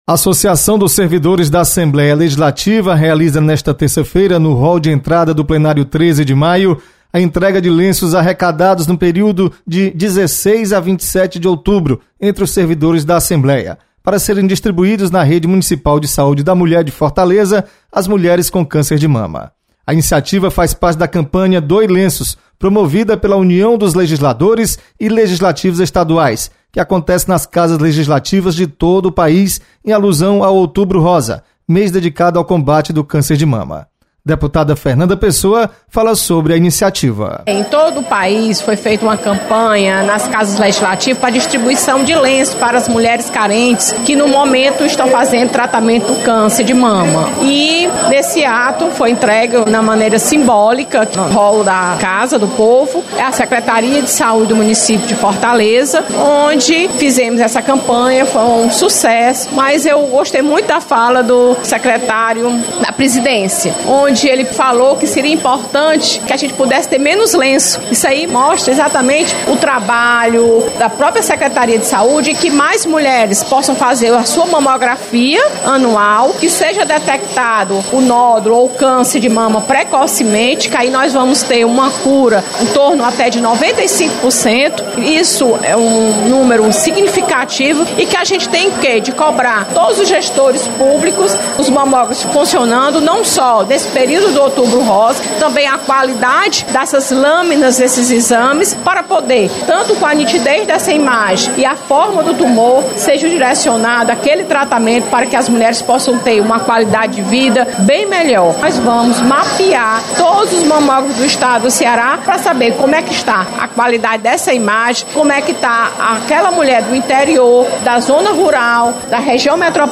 Doação de lenços para pacientes com câncer de mama acontece nesta terça-feira. Repórter